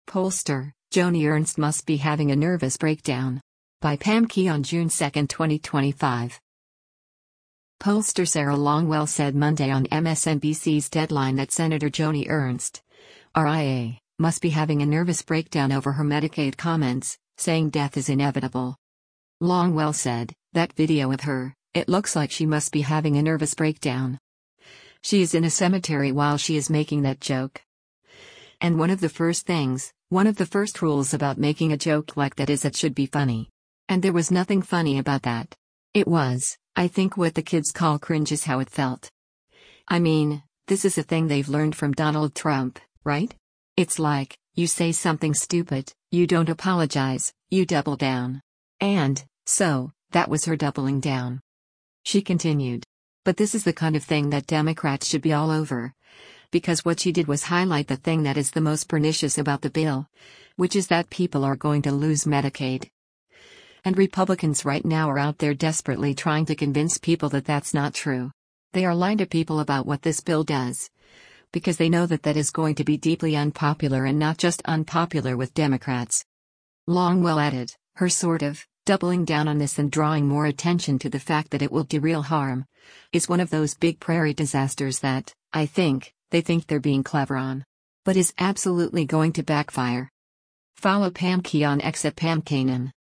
Pollster Sarah Longwell said Monday on MSNBC’s “Deadline” that Sen. Joni Ernst (R-IA) must be having a “nervous breakdown” over her Medicaid comments, saying death is inevitable.